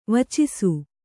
♪ vacisu